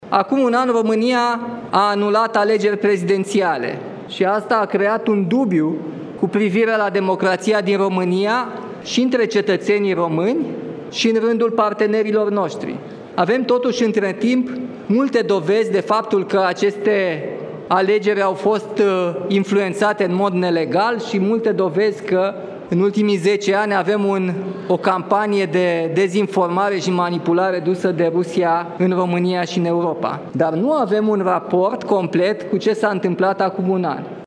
O radiografie a României – așa a arătat discursul președintelui Nicușor Dan din deschiderea recepției de Ziua Națională de la Palatul Cotroceni.